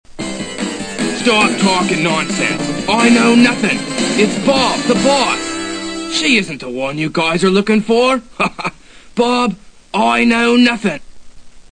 And here's some of the wonderful voice acting.
What the hell kind of accent is that?